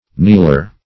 Kneeler \Kneel"er\, n.